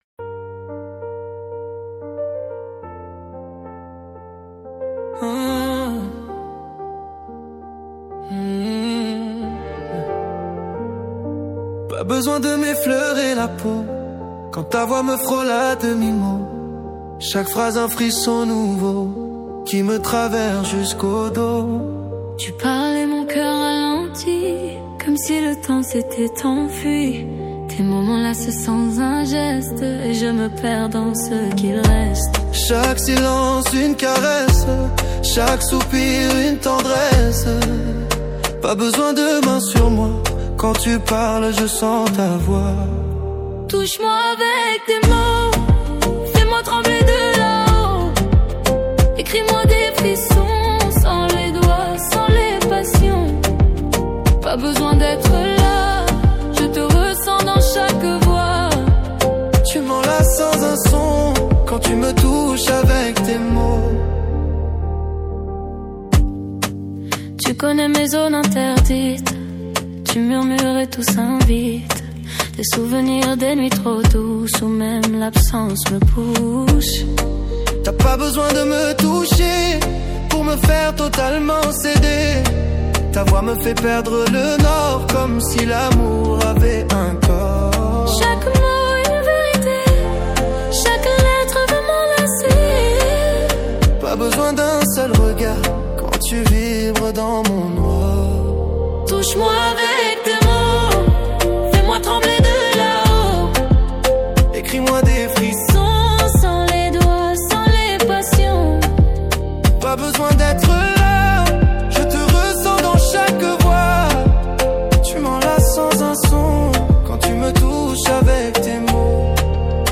| Pop